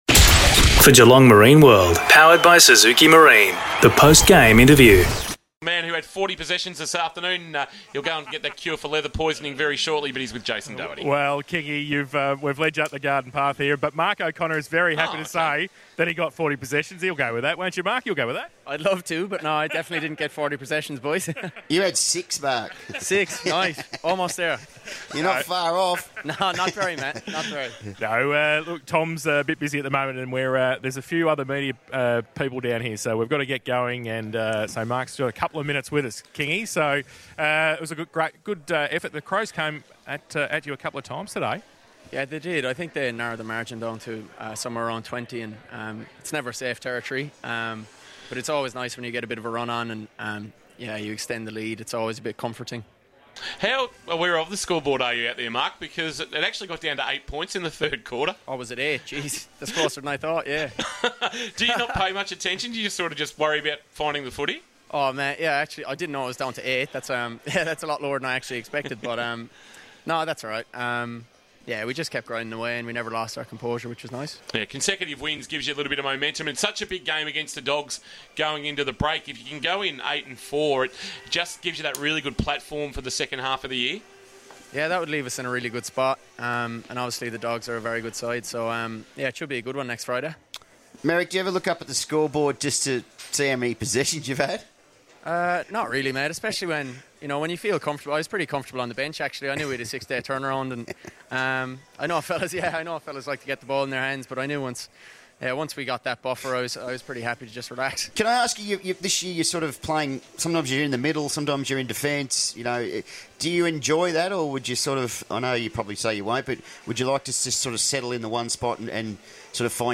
2022 - AFL ROUND 11 - GEELONG vs. ADELAIDE: Post-match Interview - Mark O'Connor (Geelong)